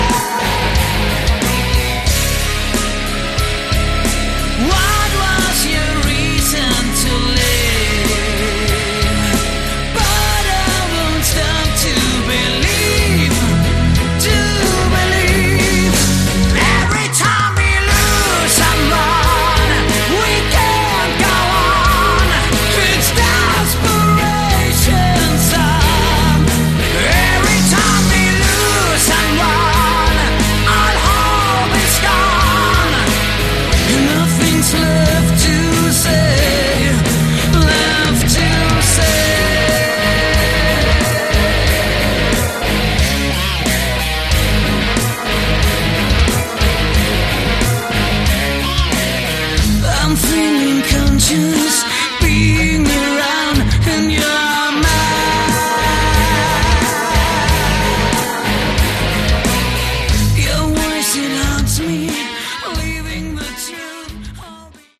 Category: Hard Rock
Like many European bands, the vocals are heavily accented.